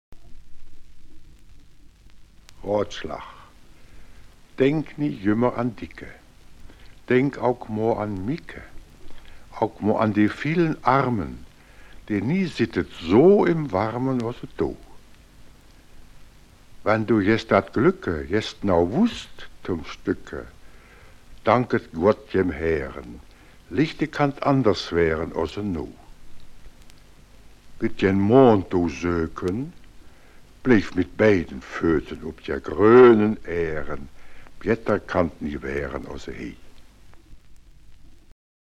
August 1965, in "Rhodener Mundart"